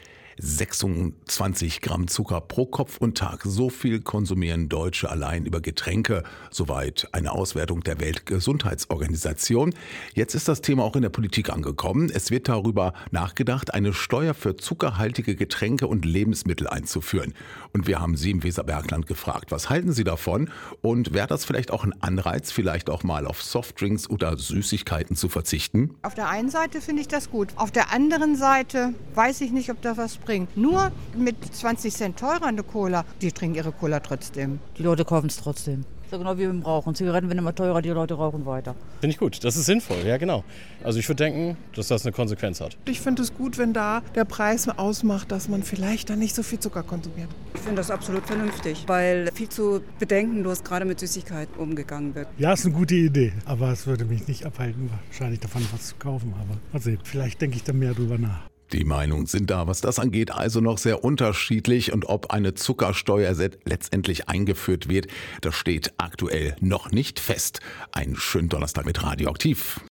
Umfrage: Wäre eine Zuckersteuer sinnvoll?
umfrage-waere-eine-zuckersteuer-sinnvoll.mp3